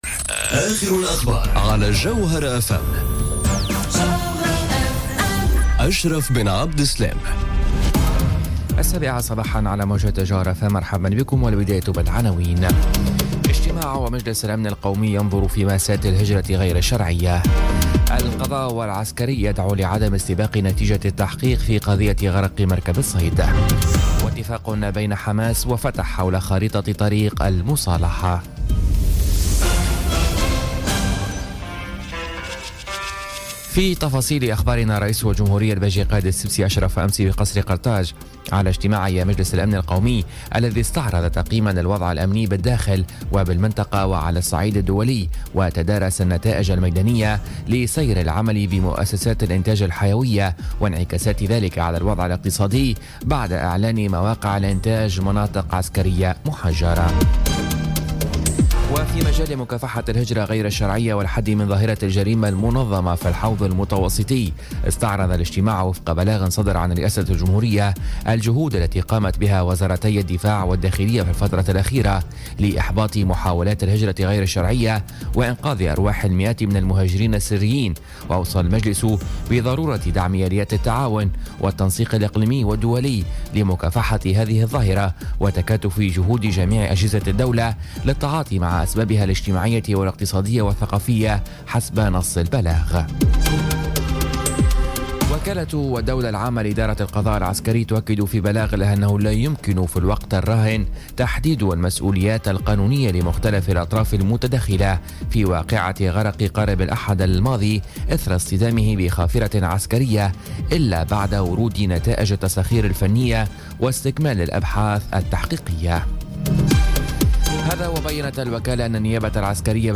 نشرة أخبار السابعة صباحا ليوم الخميس 12 أكتوبر 2017